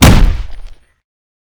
Punch_Hit_20.wav